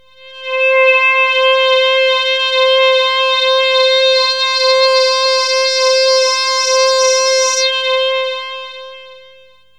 AMBIENT ATMOSPHERES-4 0005.wav